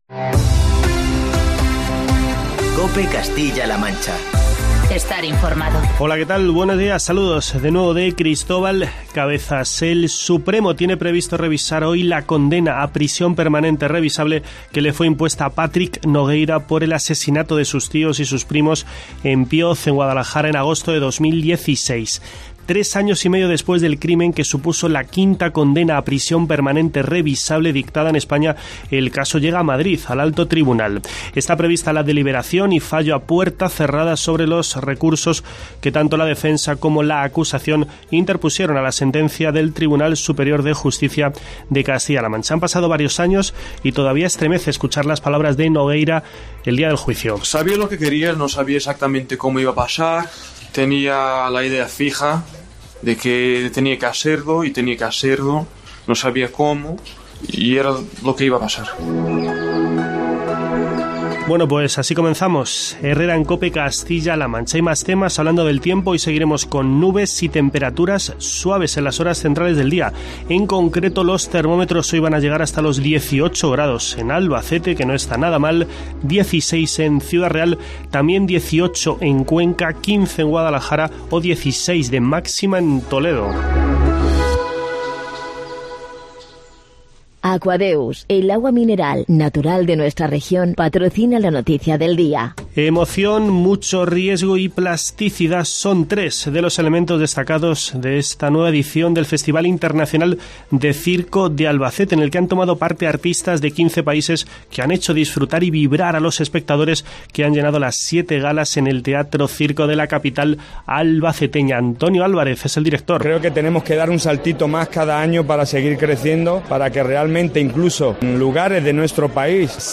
Escucha en la parte superior de esta noticia todos los detalles en los informativos matinales de COPE Castilla-La Mancha y COPE Toledo.